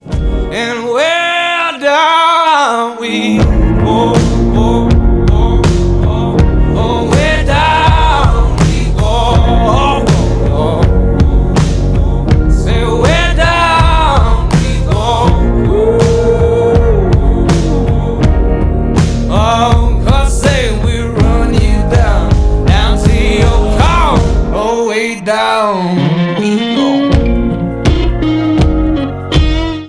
• Alternative